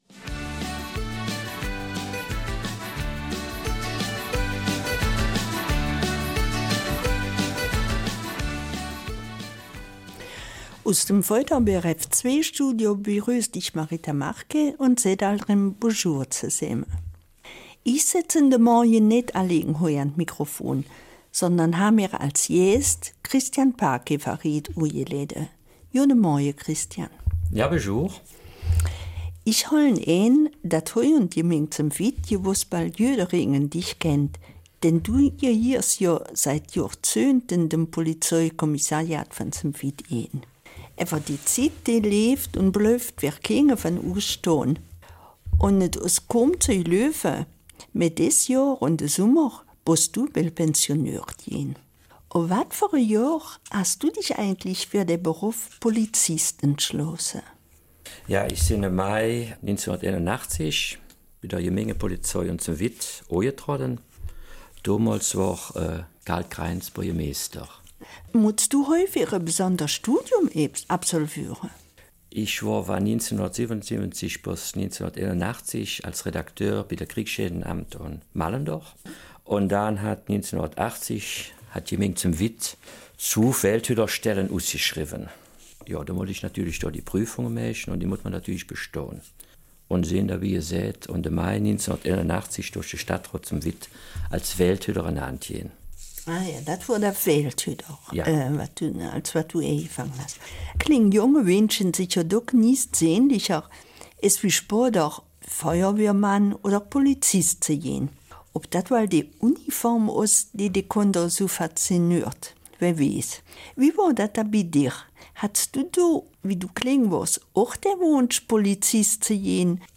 Eifeler Mundart: Die Polizei, dein Freund und Helfer